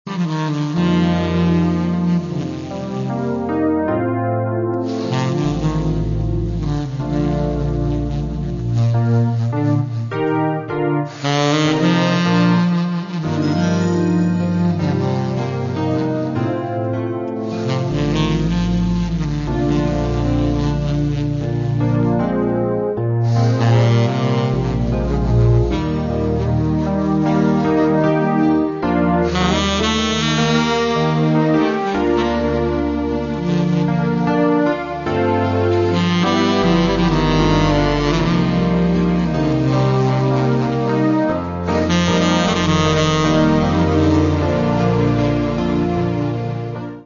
Catalogue -> Variety Art -> Instrumental Variations